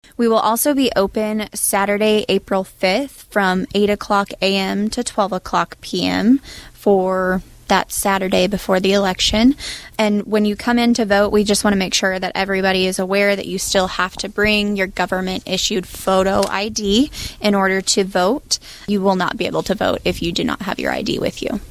Burton also reminds voters of the advance voting opportunity the Saturday prior to the election.